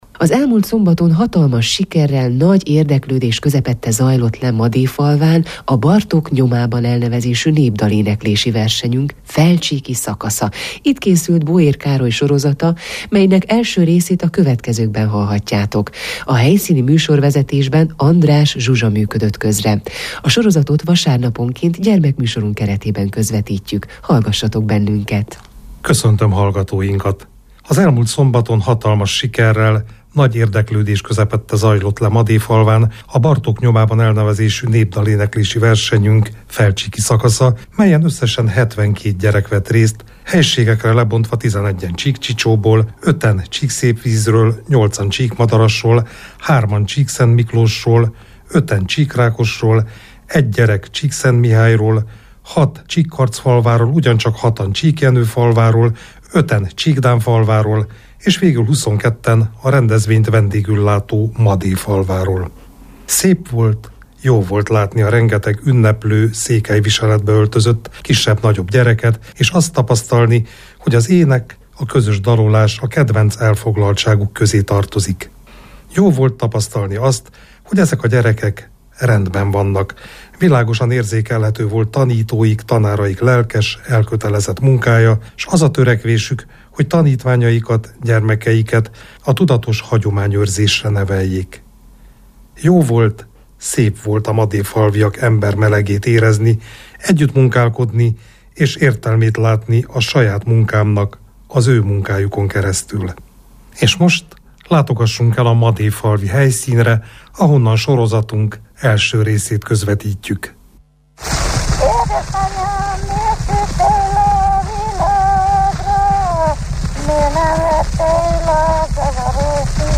Az elmúlt szombaton, hatalmas sikerrel, nagy érdeklődés közepette zajlott le Madéfalván a Bartók nyomában elnevezésű népdaléneklési versenyünk felcsíki szakasza.